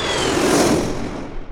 sk09_missile.wav